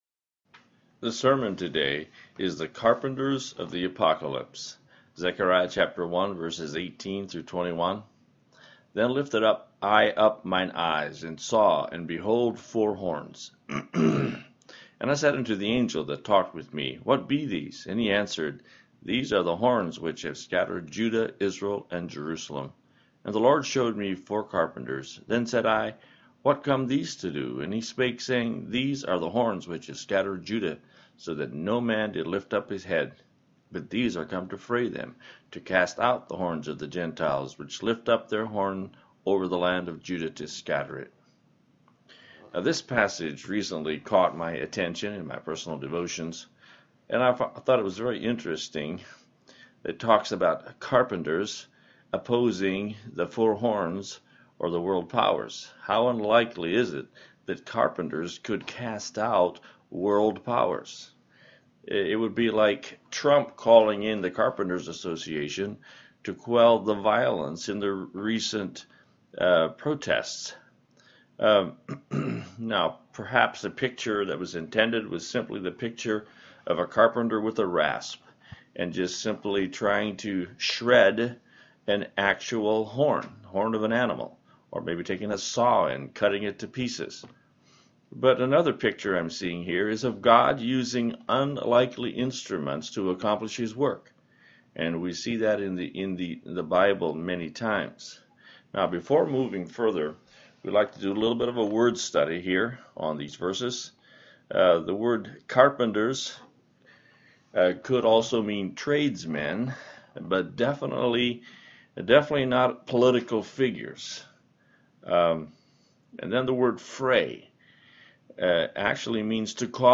The Carpenters of the Apocolypse, audio sermon.mp3